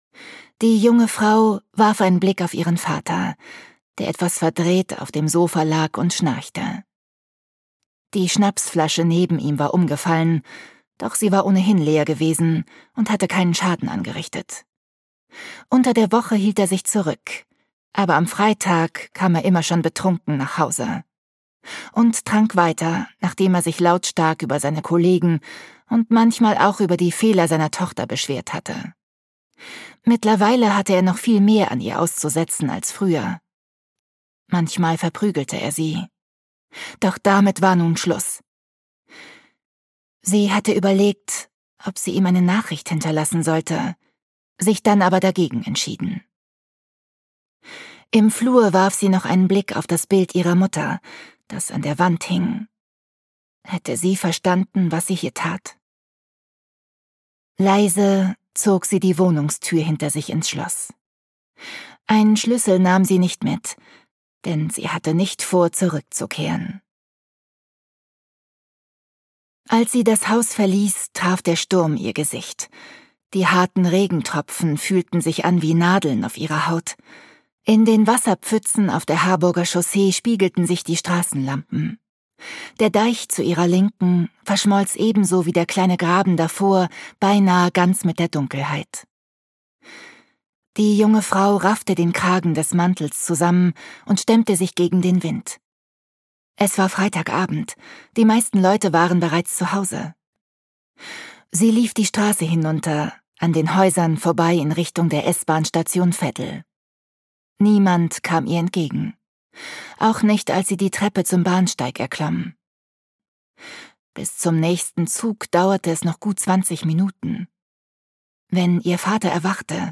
Sturmherz - Corina Bomann - Hörbuch